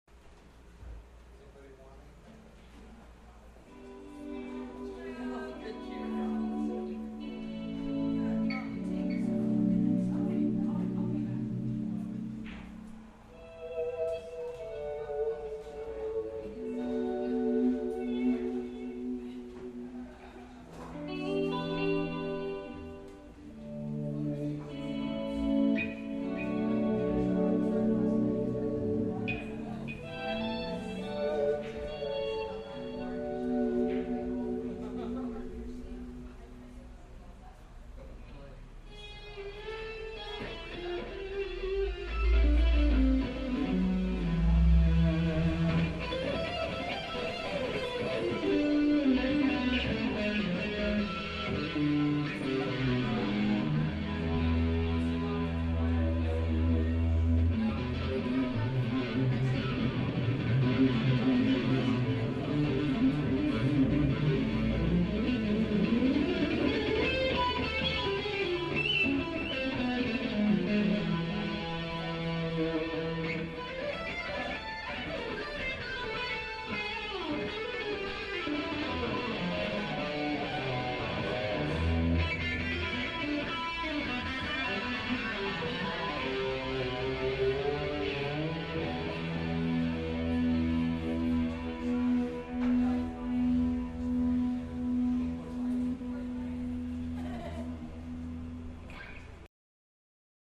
"Live" at the Metaphor Cafe in Escondido, CA
guitar